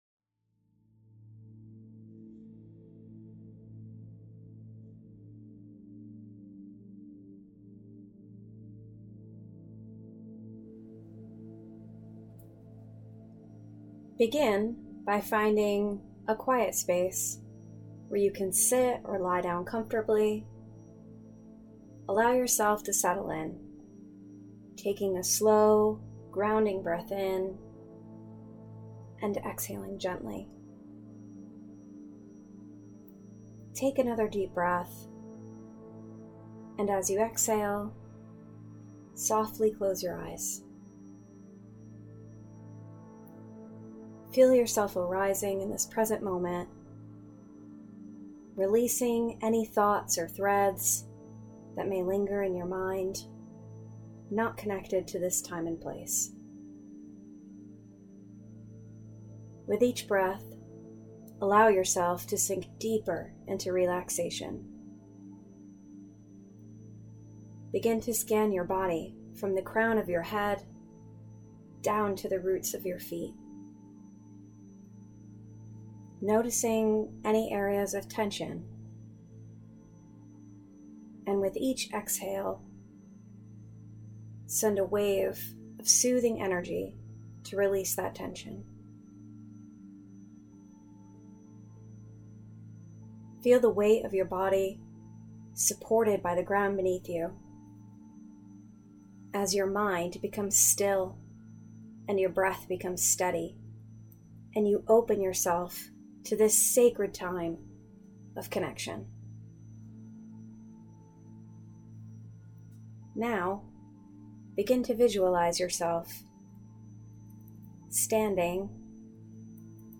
Sacred Flame: A Guided Meditation to Connect with Your Higher Self
In this beginner-friendly practice, I’ll gently guide you through a visualization to connect with your Higher Self using the symbol of the Sacred Flame — a steady, loving light within you that never burns out.